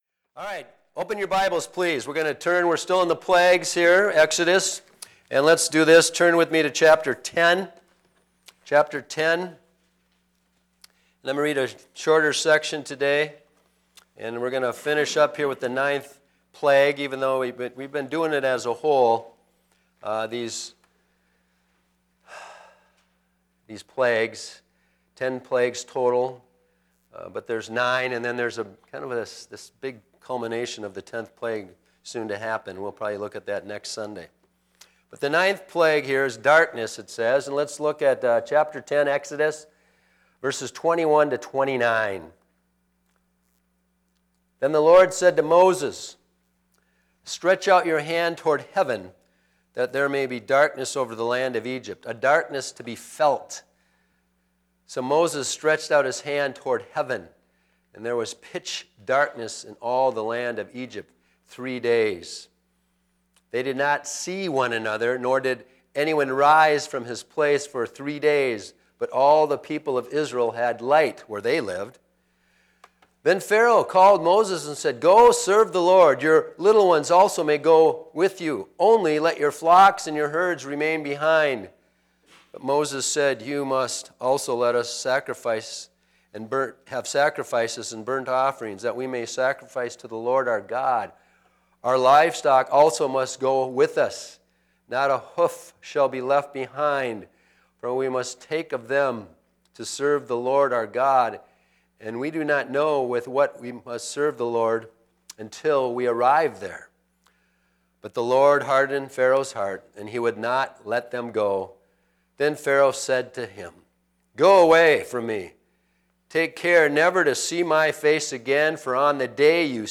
Sermon Series: Exodus: The Glorious God of Rescue and Promise.